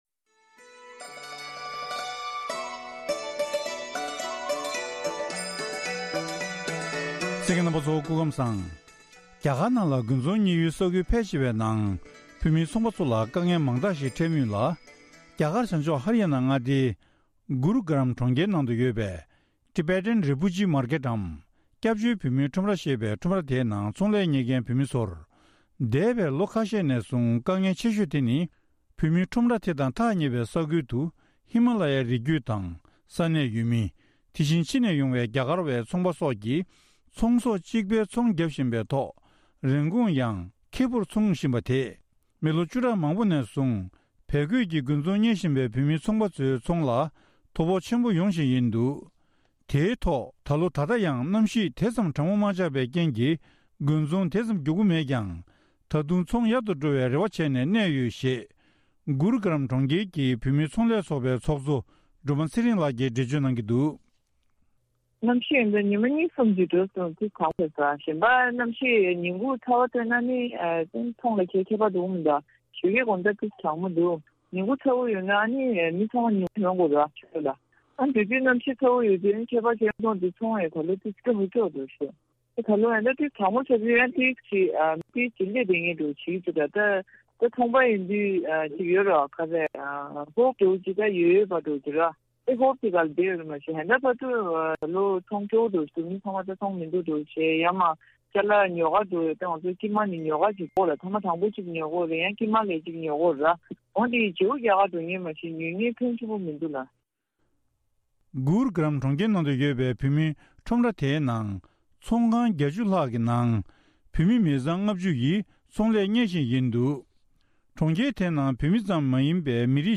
བཀའ་འདྲི་ཕྱོགས་སྒྲིགས་ཞུས་པ་ཞིག་གསན་རོགས་གནང་།